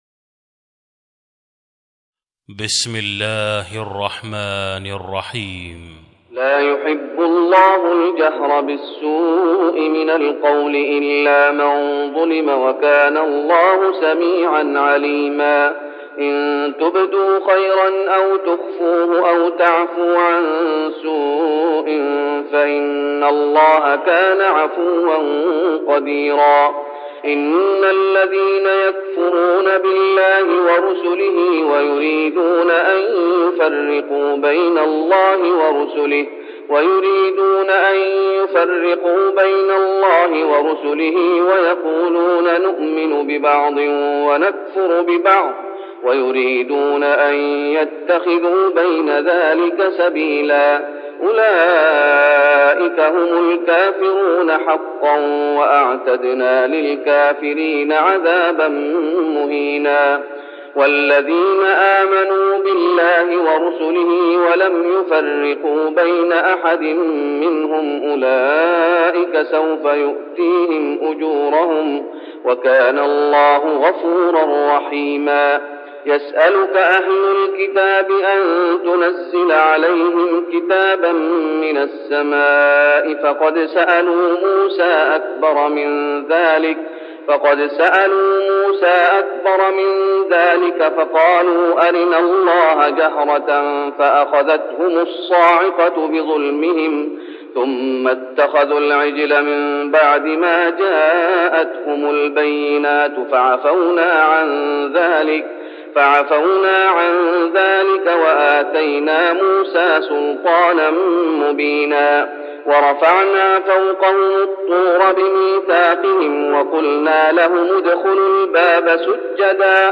تهجد رمضان 1410هـ من سورة النساء (148-176) Tahajjud Ramadan 1410H from Surah An-Nisaa > تراويح الشيخ محمد أيوب بالنبوي عام 1410 🕌 > التراويح - تلاوات الحرمين